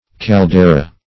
caldera - definition of caldera - synonyms, pronunciation, spelling from Free Dictionary